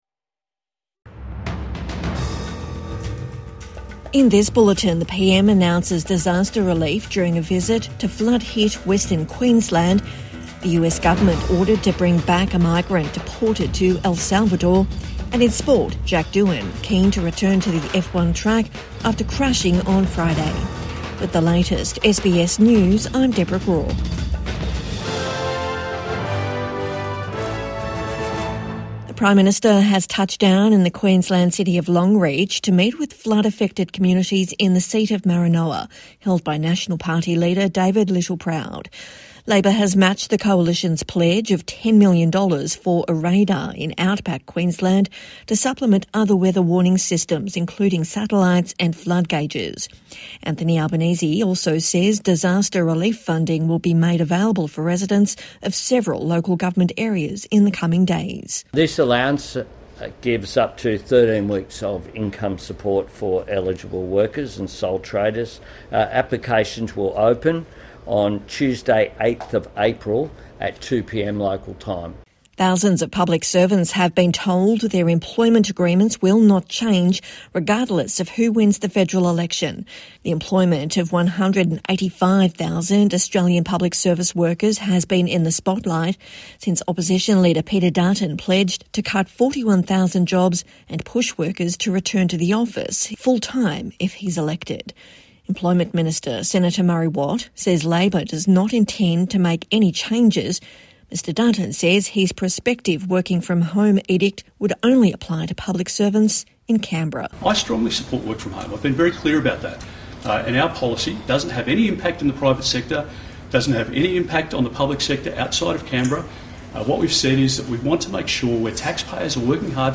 Evening News Bulletin 5 April 2025